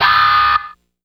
Index of /90_sSampleCDs/Zero-G - Total Drum Bass/Instruments - 2/track43 (Guitars)
08 Waah E7#9.wav